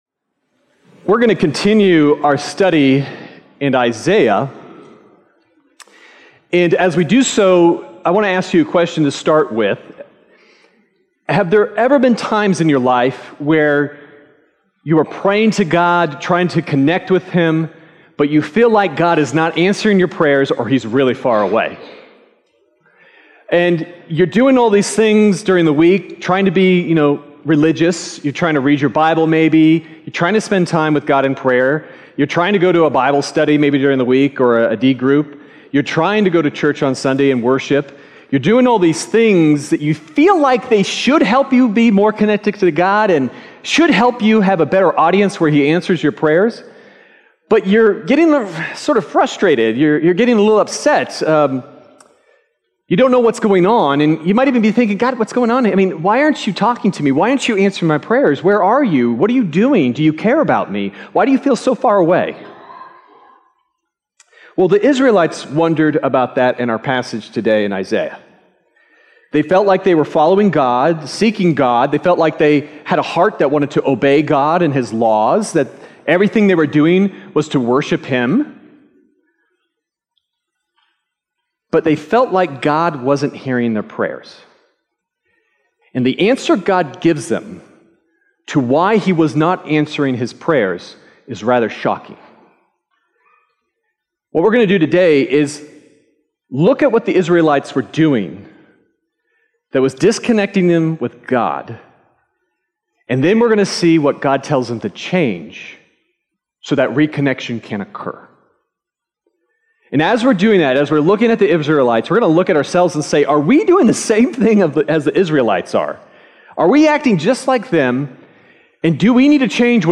Sermon Detail
February_11th_Sermon_Audio.mp3